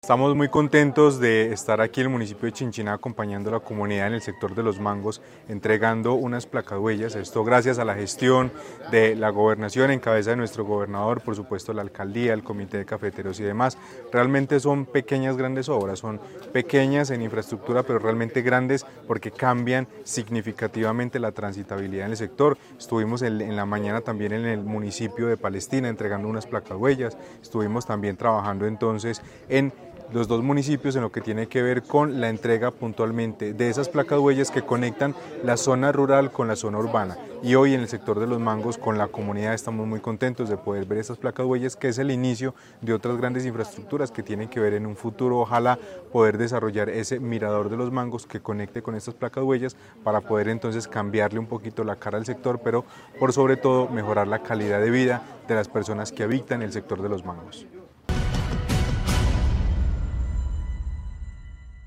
Carlos Anderson García, gobernador (e) de Caldas